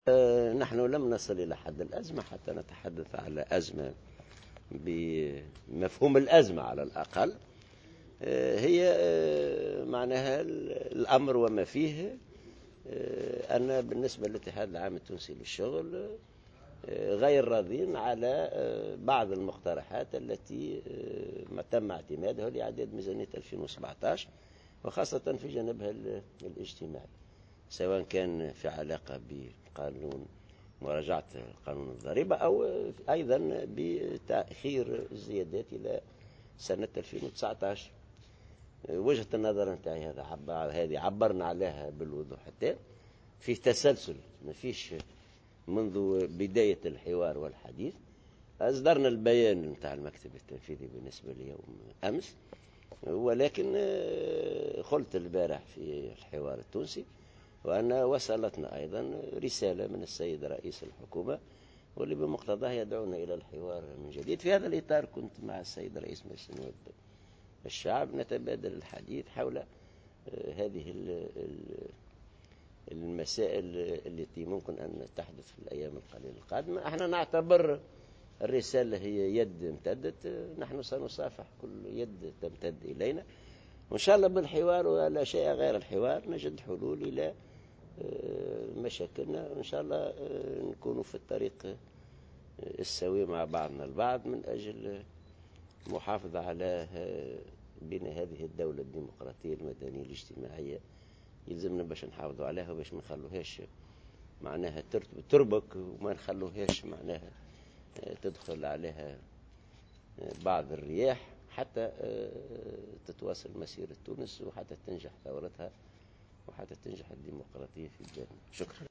ونفى العباسي في تصريح لمراسل "الجوهرة أف أم" إثر اللقاء الذي جمعه صباح اليوم برئيس مجلس نواب الشعب، محمد الناصر وجود أزمة بين الاتحاد والحكومة، مضيفا أن المنظمة الشغيلة غير راضية على بعض المقترحات التي تم اعتمادها في قانون ميزانية الدولة لسنة 2017، خاصة في جانبها الاجتماعي سواء تعلّق ذلك بمراجعة قانون الضريبة أو تأجيل الزيادة في الأجور .